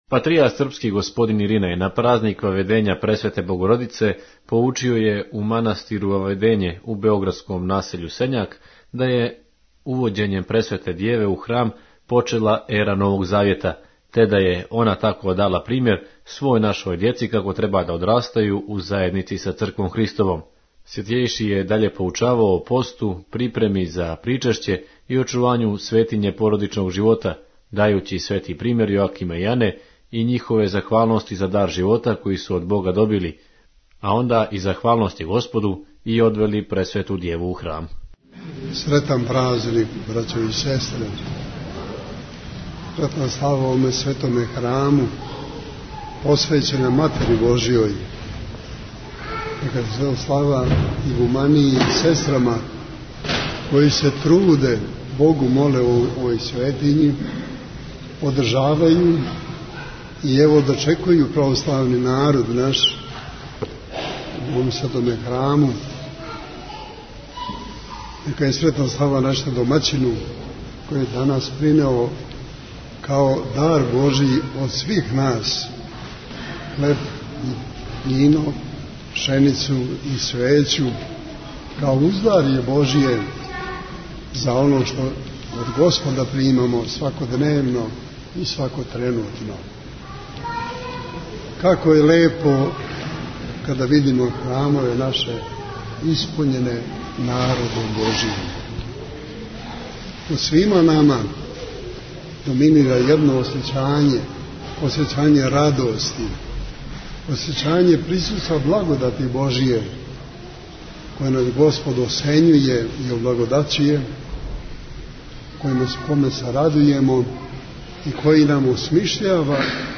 Патријарх српски Иринеј на празник Ваведења Пресвете Богородице поучио је у манастиру Ваведење у београдском насељу Сењаку да је увођењем Пресвете Дјеве у Храм почела ера Новог Завјета, те да је Она тако дала примјер свој нашој дјеци како треба да одрастају у заједници са Црквом Христовом. Свјатјејши је даље поучавао о посту, припреми за Причешће и очувању светиње породичног живота дајући свети примјер Јоакима и Ане и њихове захвалности за дар живота који су од Бога добили, а онда из захвалности Господу и одвели Пресвету Дјеву у Храм.